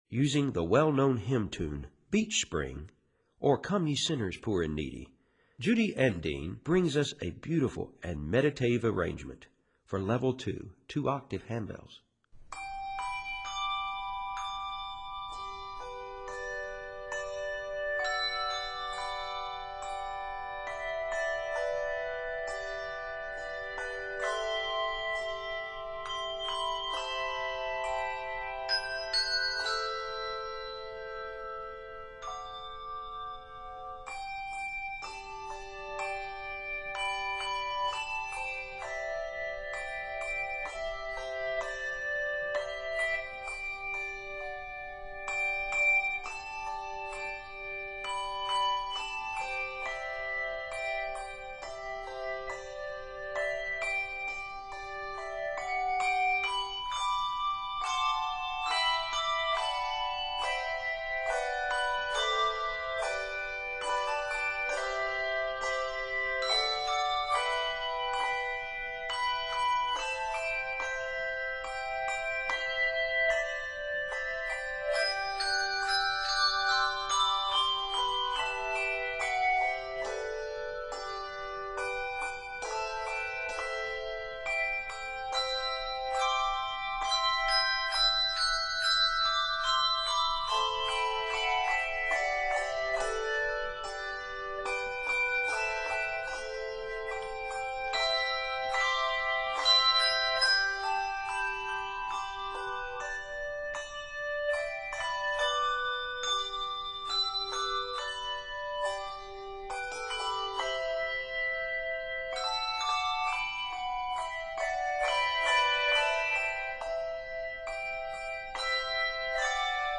beautiful, meditative arrangement